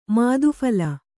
♪ madhu phala